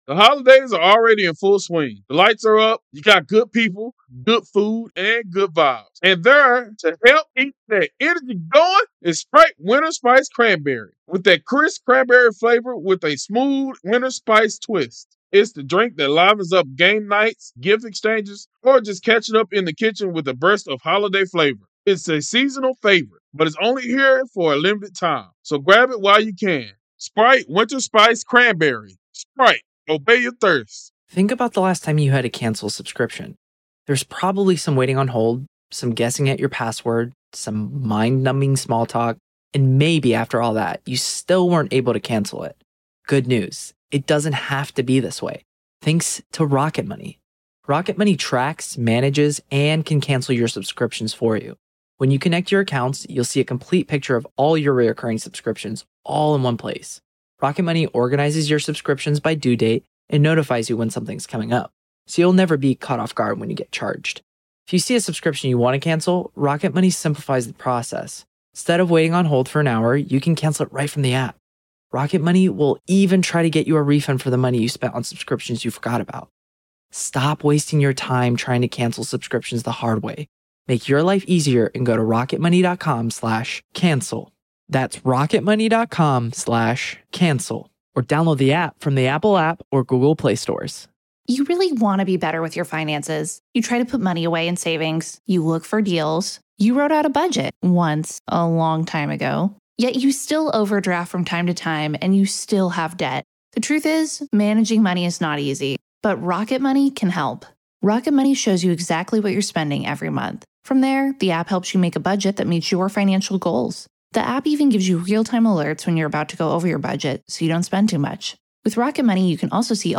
The Trial Of Alex Murdaugh | FULL TRIAL COVERAGE Day 14 - Part 3